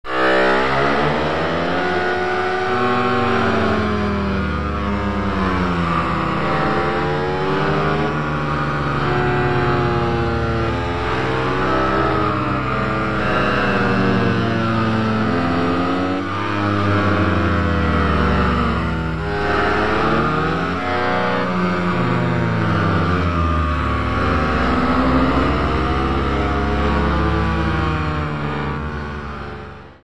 This page contains some example sounds produced by Tao instruments.
A low pitched stringed instrument with each string independently bowed with algorithmically controlled glissandi